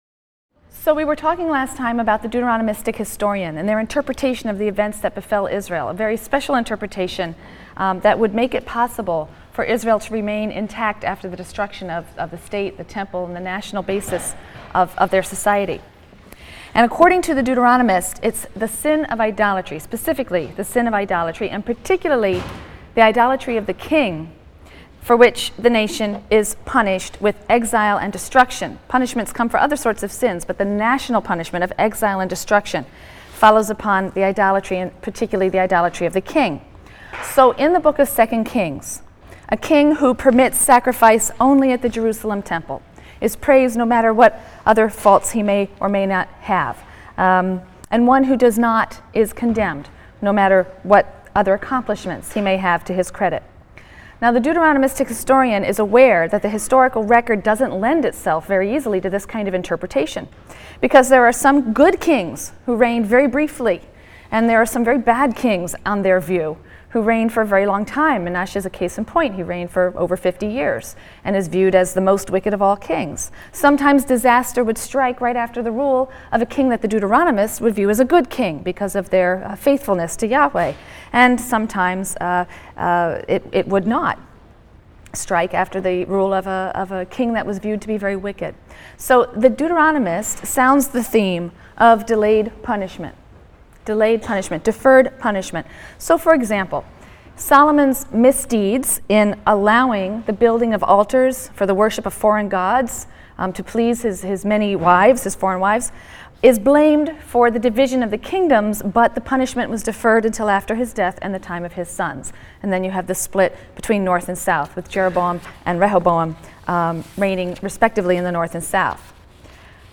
RLST 145 - Lecture 15 - Hebrew Prophecy: The Non-Literary Prophets | Open Yale Courses